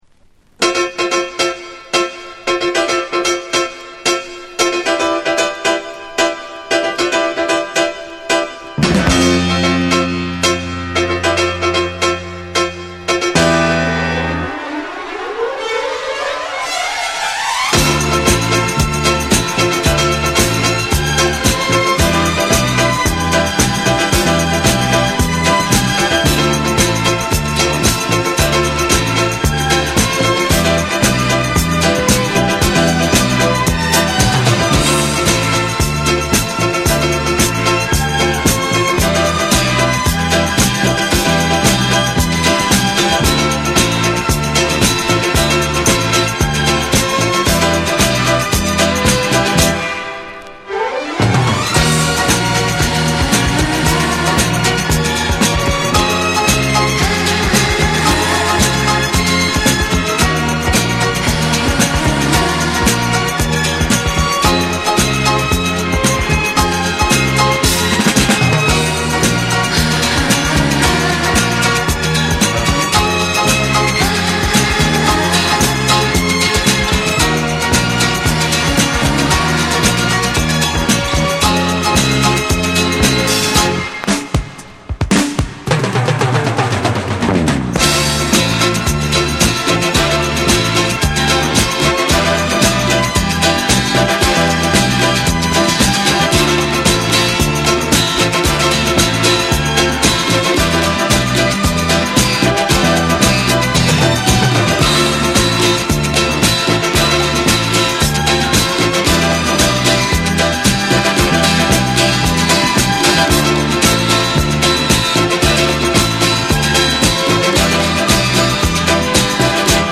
まさに降り注ぐようなエレガントなストリングスが舞う元祖多幸感ディスコ・ナンバー
DANCE CLASSICS / DISCO